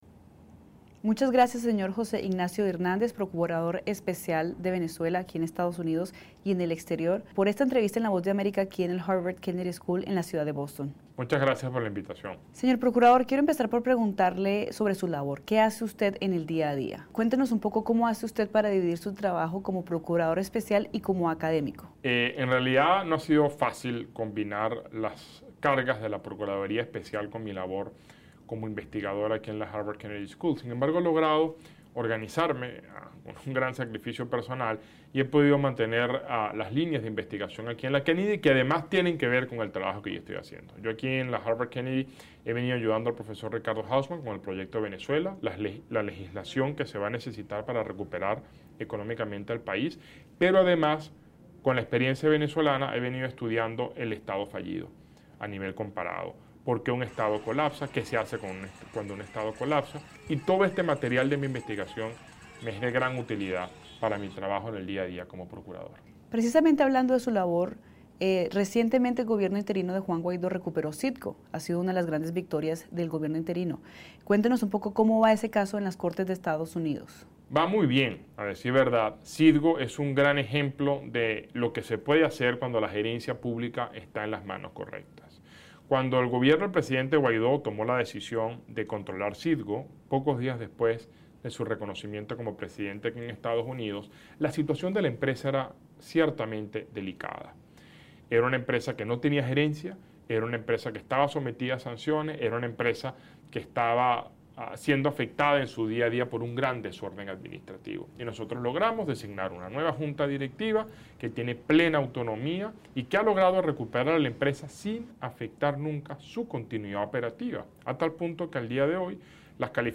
Entrevista con el experto en temas de Asia Contemporánea